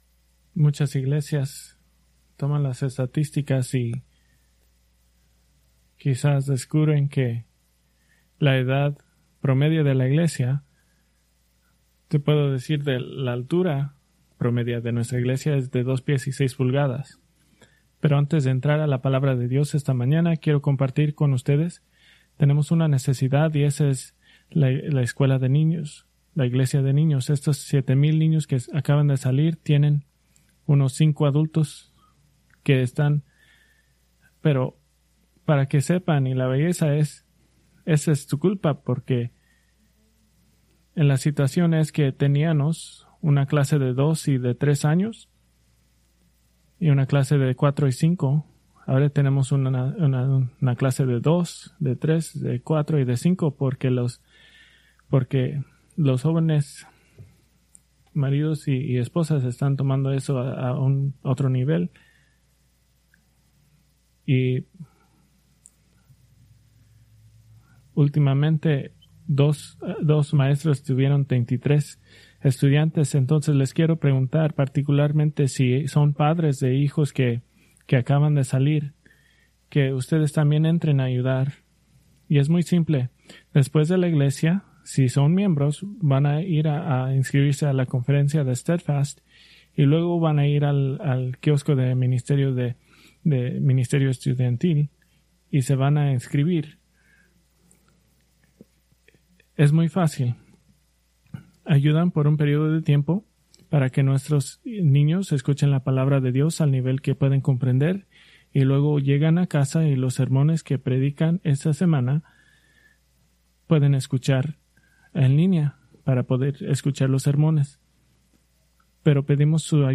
Preached August 31, 2025 from Escrituras seleccionadas